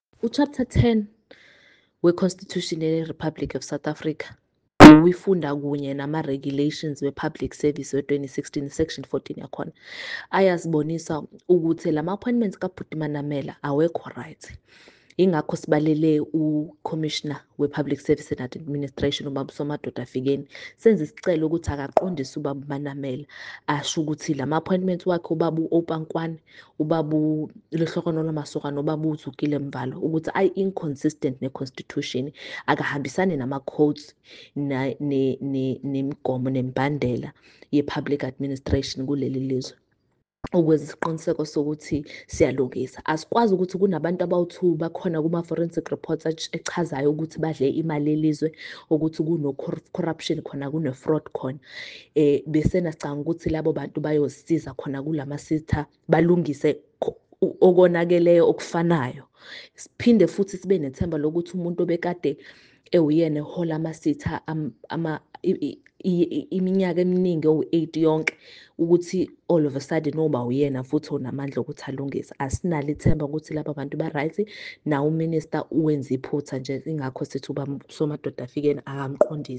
IsiZulu soundbites by Karabo Khakhau MP.